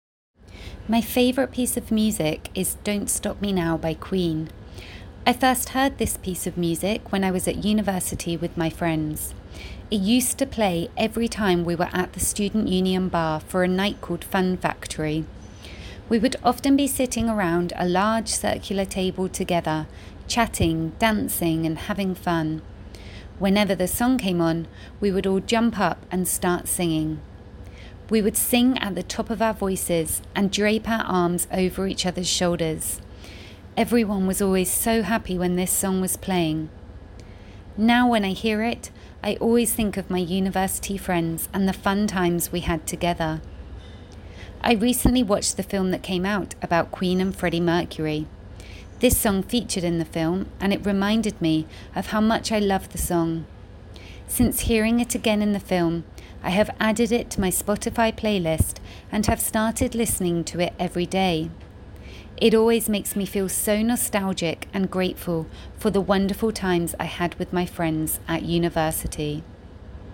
Model Answer: Music